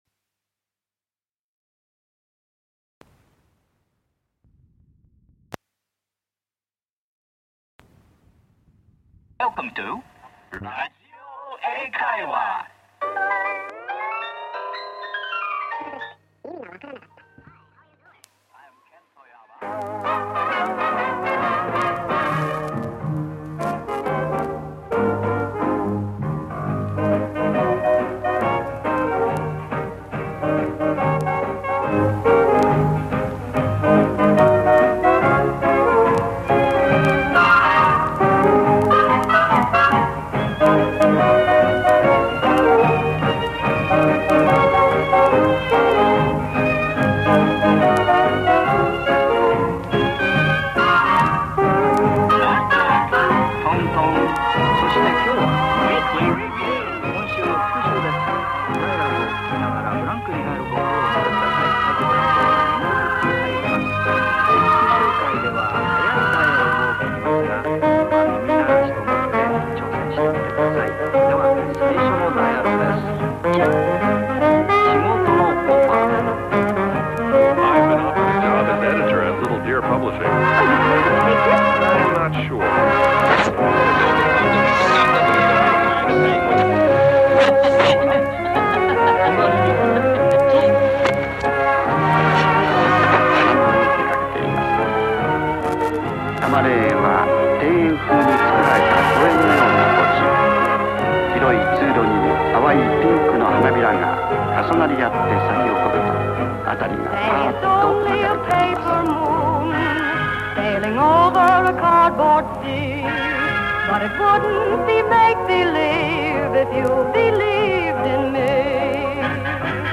Смесь хитов 1920-1940 годов.